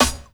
SNARE_CREEP.wav